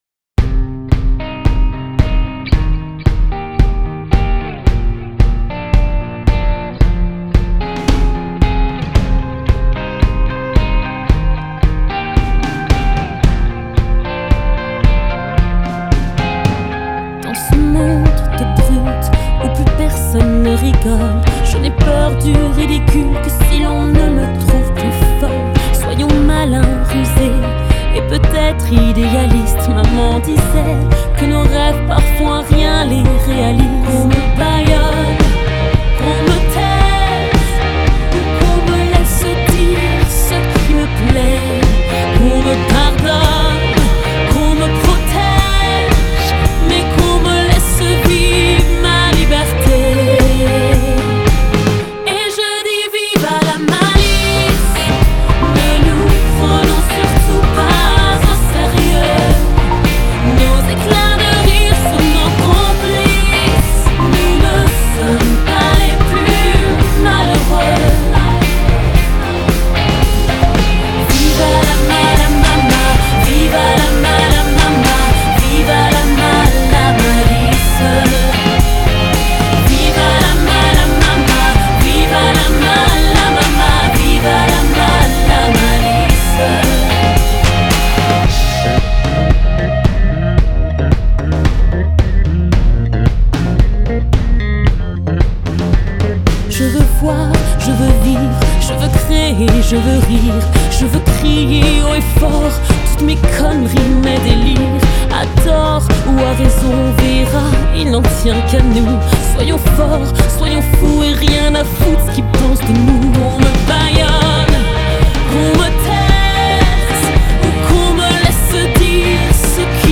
Genre: Pop, Dance, R&B, Soul, French